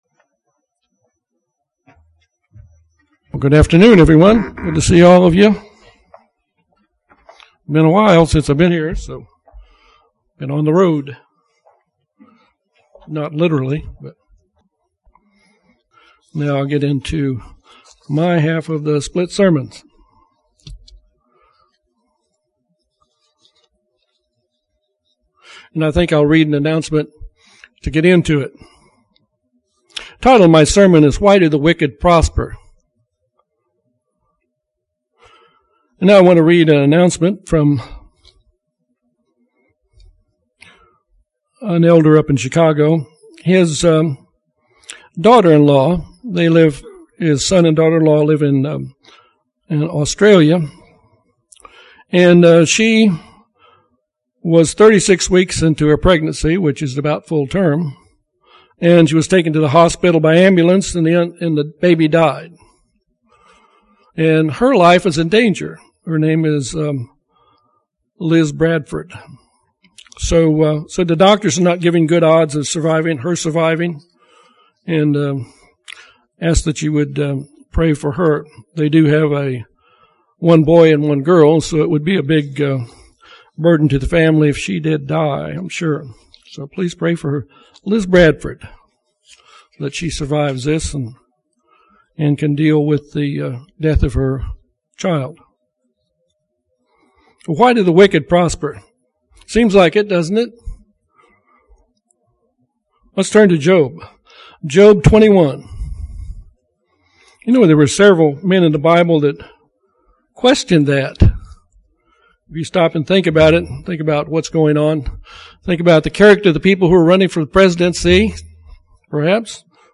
Sermons
Given in Little Rock, AR